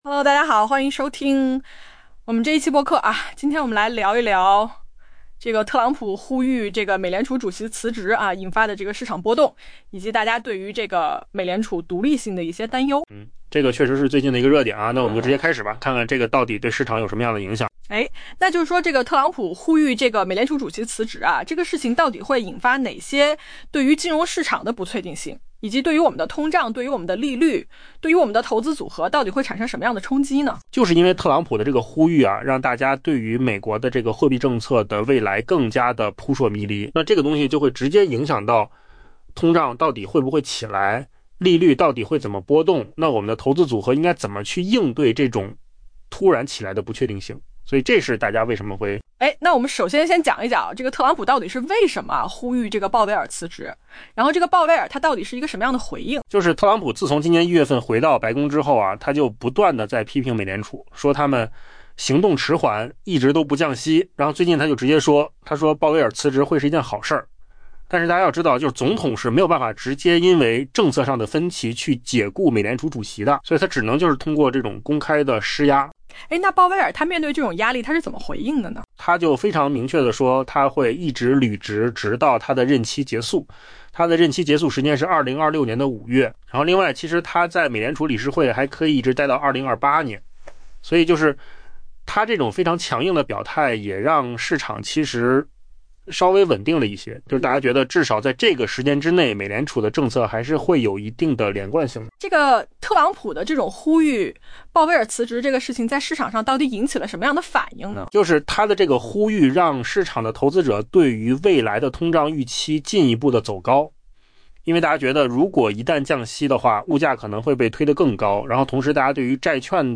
AI播客：换个方式听新闻 下载mp3
音频由扣子空间生成